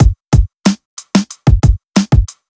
FK092BEAT4-L.wav